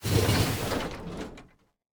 train-tie-2.ogg